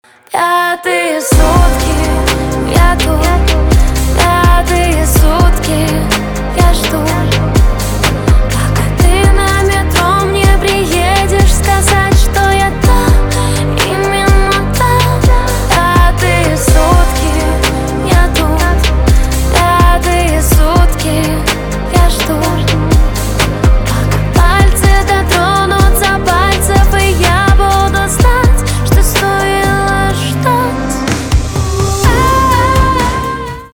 поп
битовые , басы , чувственные , романтические